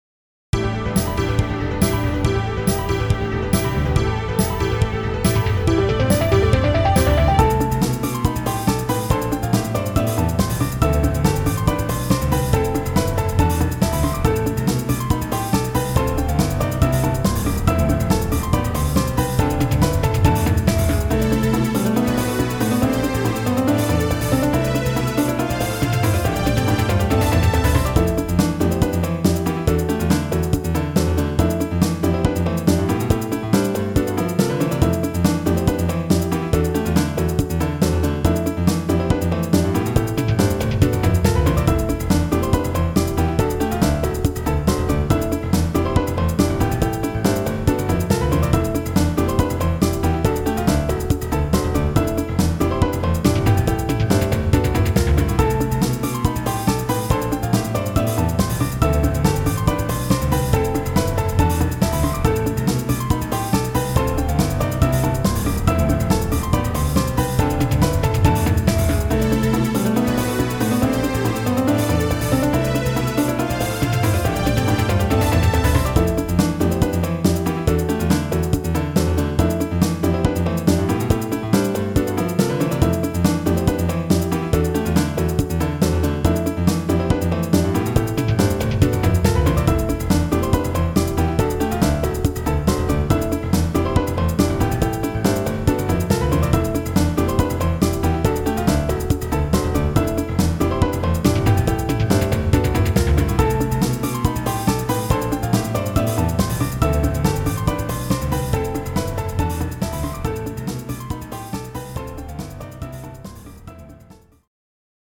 趣味でゲームBGMの適当な簡易アレンジを作って遊んでます。
主にピアノ演奏をメインにして、IIとIIIの伴奏を少し追加した程度です。こっちのほうがすっきりして聴きやすいかも。